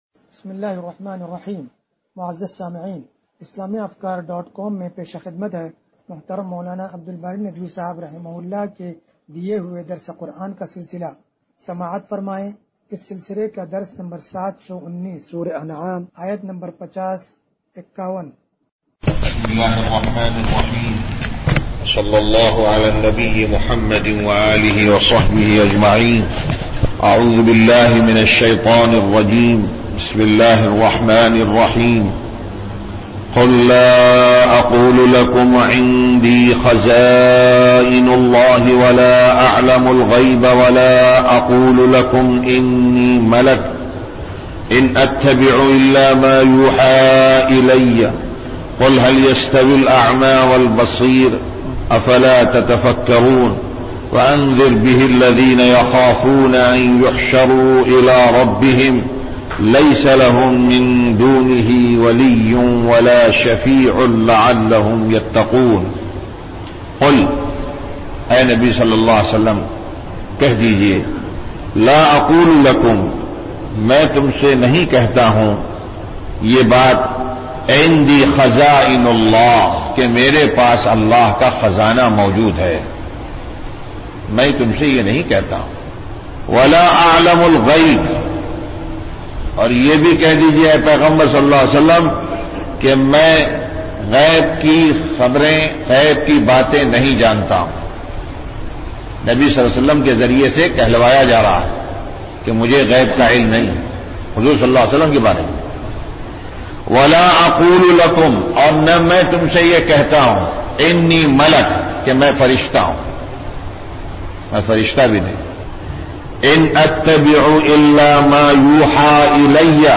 درس قرآن نمبر 0719
درس-قرآن-نمبر-0719.mp3